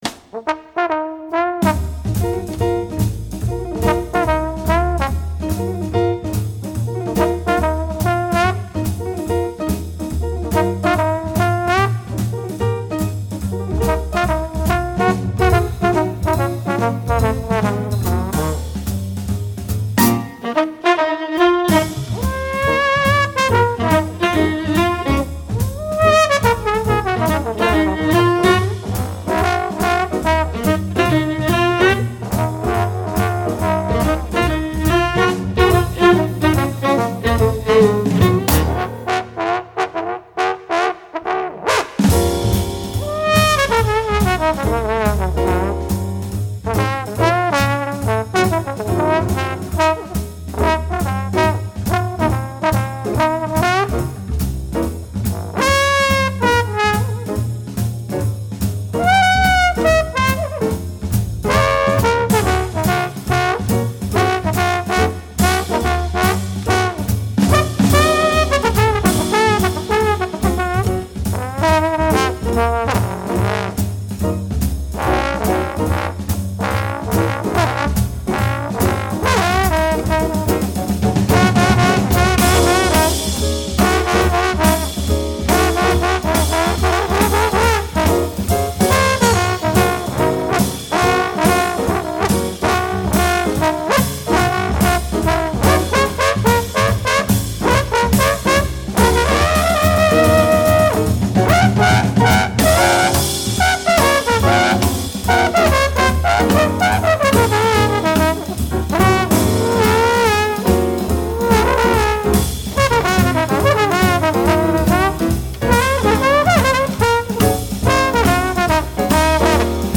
trombone, trumpet, soprano trombone, vocals
tenor & soprano sax
violin
piano
bass
drums
vocals